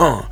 Vox
Uh.wav